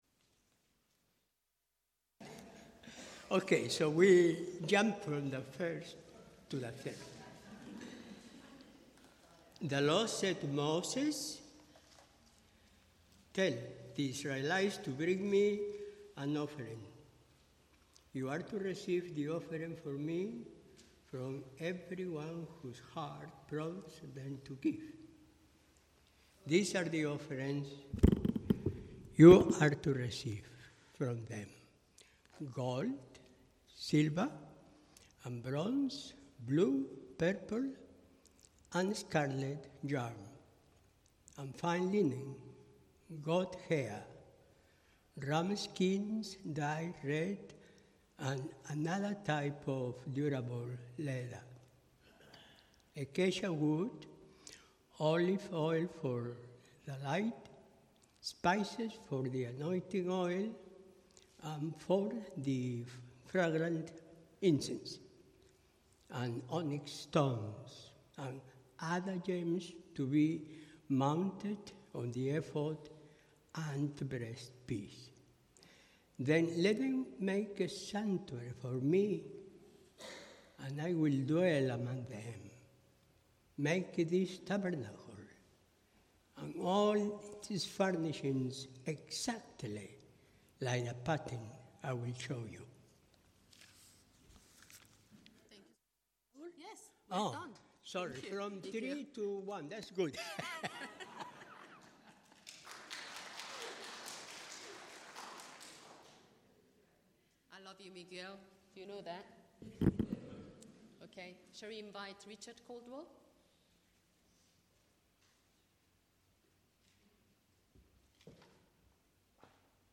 Sunday Service
Building God's Dwelling Place Sermon